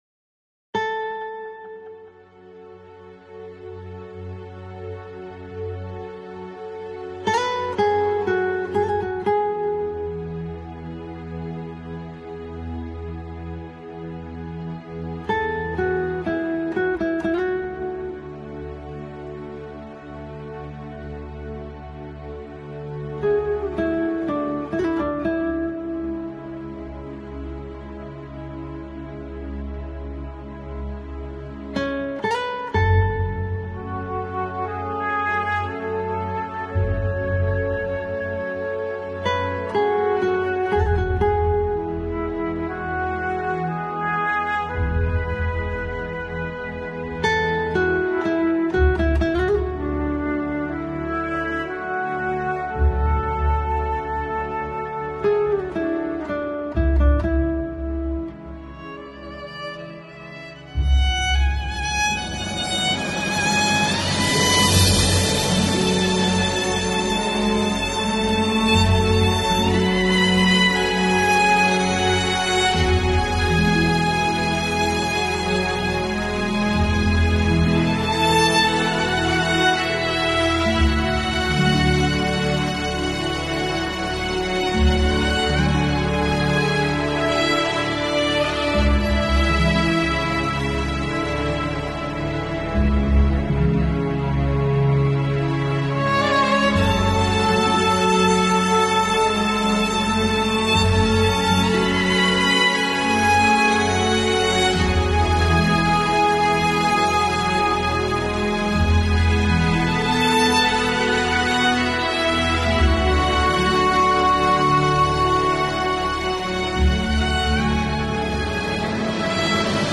بیکلام-سوزناک.mp3